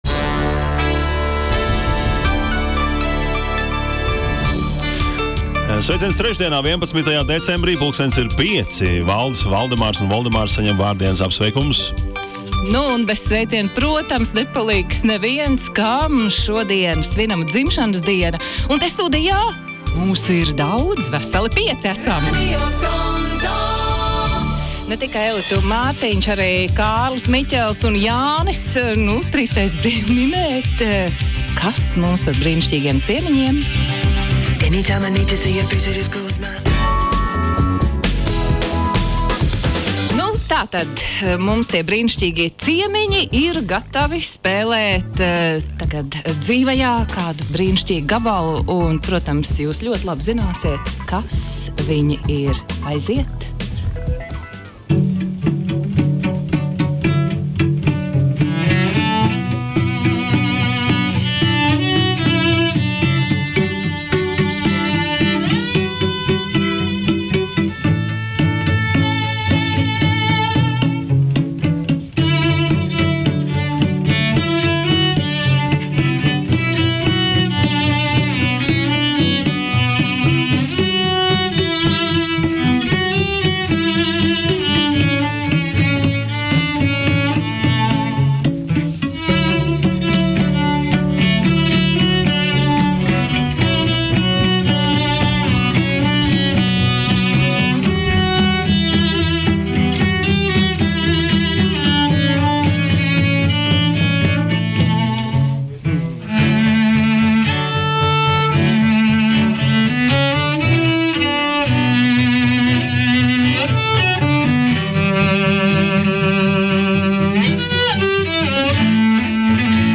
INTERVIJAS